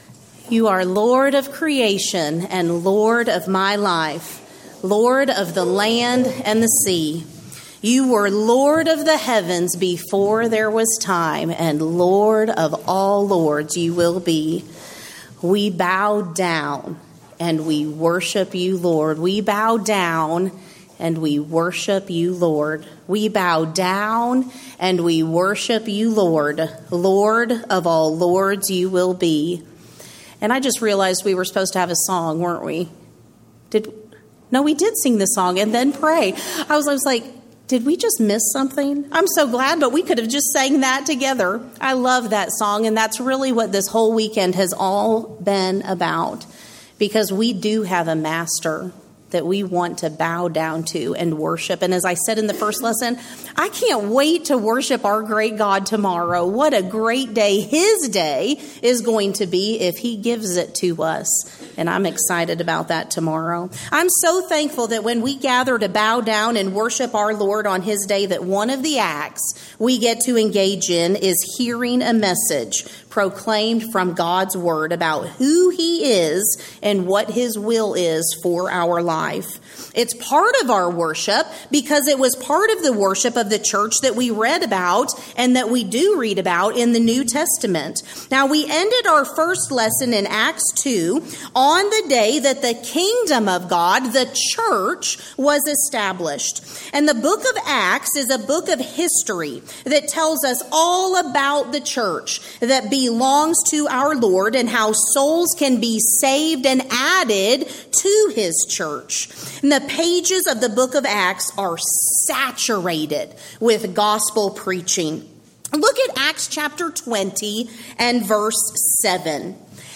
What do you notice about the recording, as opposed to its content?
Event: 5th Annual Women of Valor Ladies Retreat